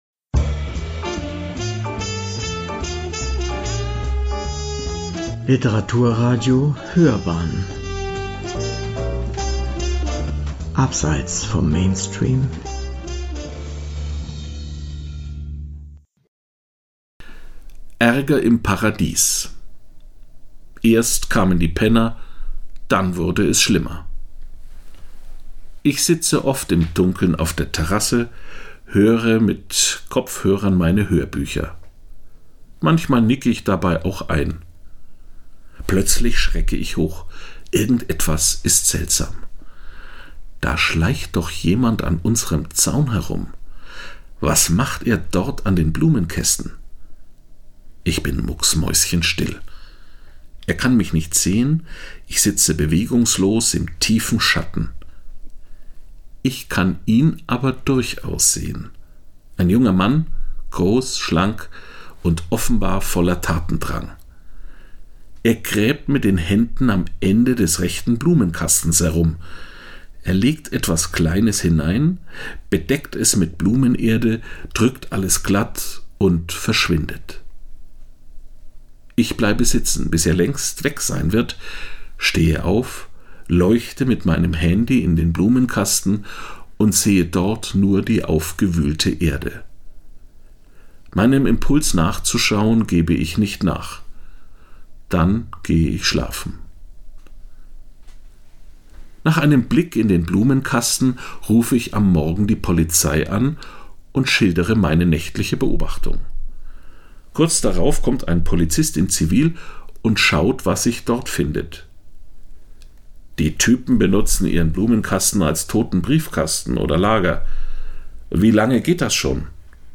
eine Kolumne